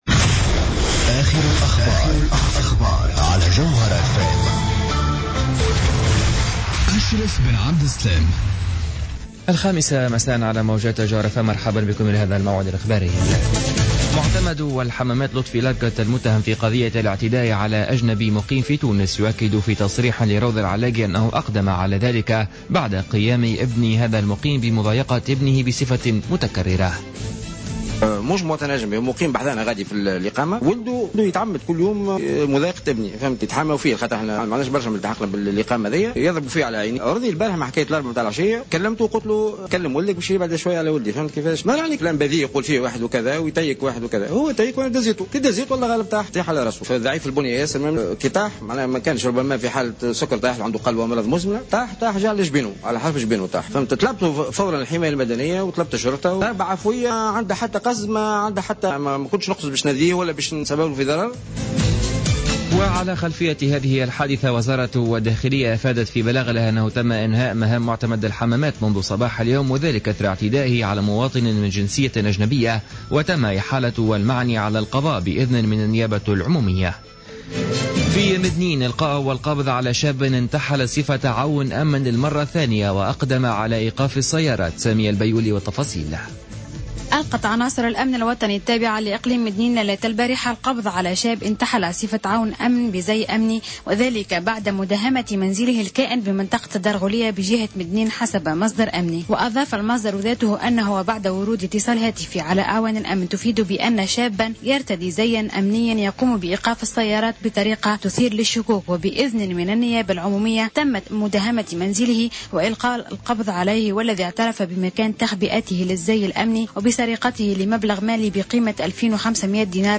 Journal Info 17h00 du mercredi 15 juillet 2015